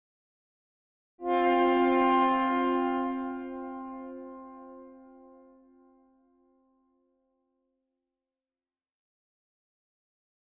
Tuba Tone 4 - Duet, Higher